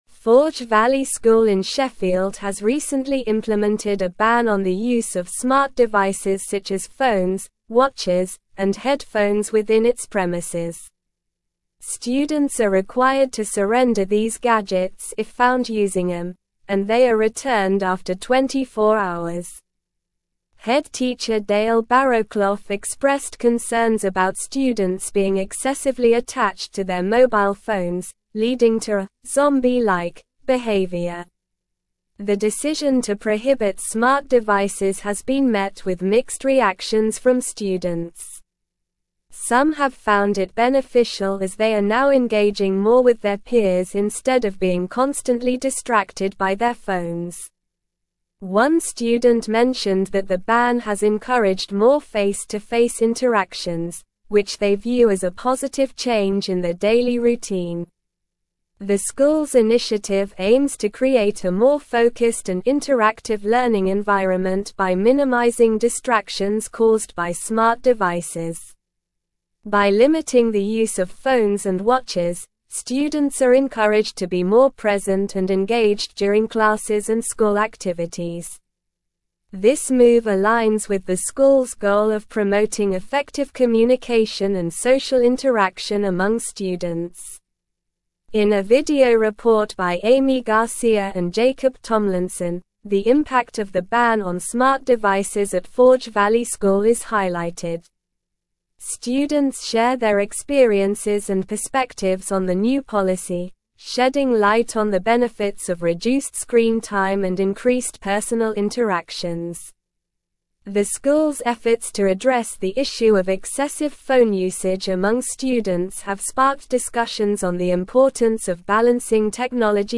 Slow
English-Newsroom-Advanced-SLOW-Reading-Forge-Valley-School-Implements-Ban-on-Smart-Devices.mp3